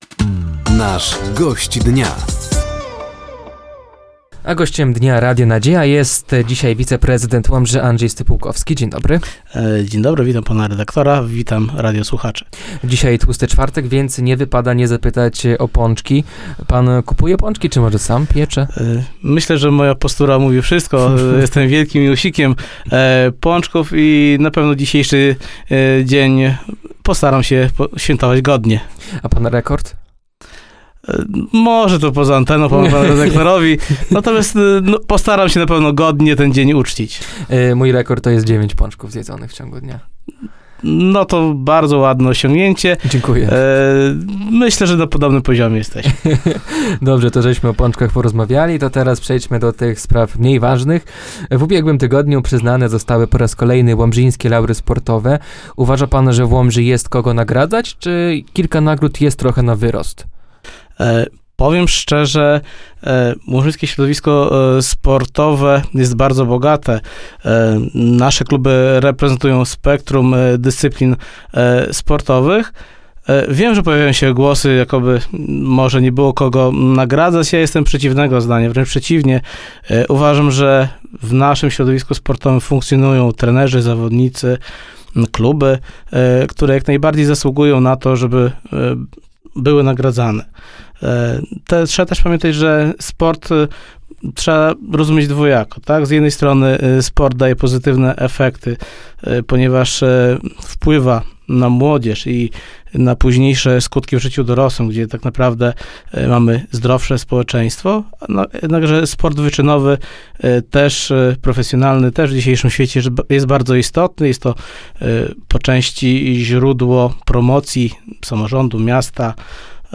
“Gościem Dnia” Radia Nadzieja był Andrzej Stypułkowski, wiceprezydent Łomży.